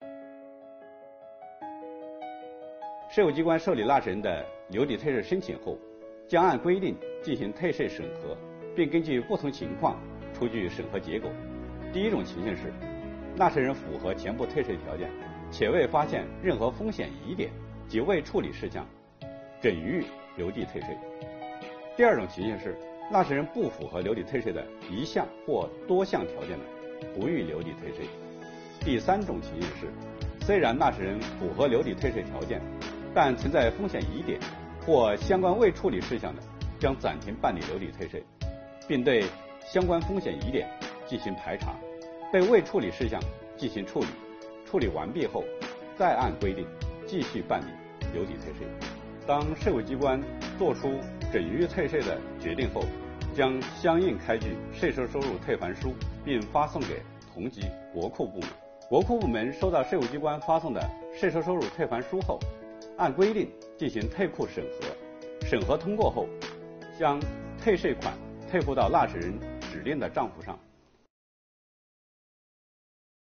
本期课程由国家税务总局货物和劳务税司副司长刘运毛担任主讲人，对2022年增值税留抵退税新政进行详细讲解，方便广大纳税人更好地理解和享受政策。今天我们来学习：税务机关如何核准纳税人的留抵退税申请？何时退还税款？